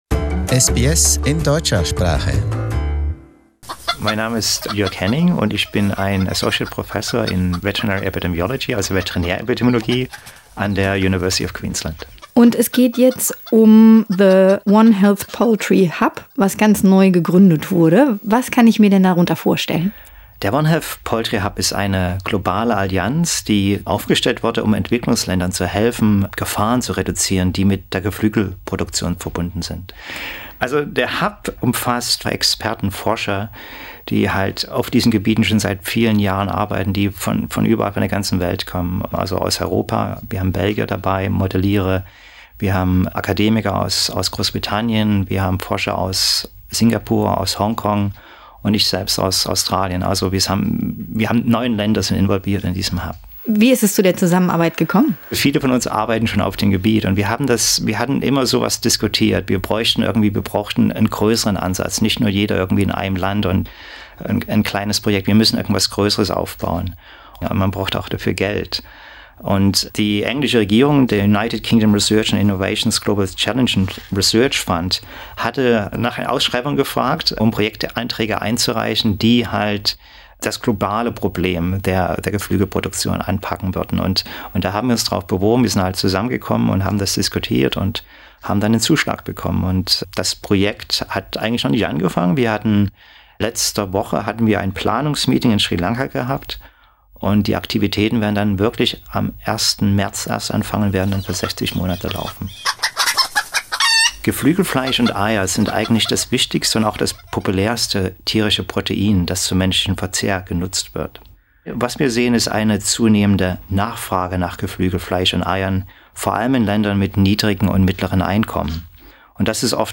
SBS German